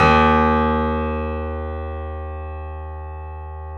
Index of /90_sSampleCDs/E-MU Producer Series Vol. 5 – 3-D Audio Collection/3D Pianos/BoesPlayHardVF04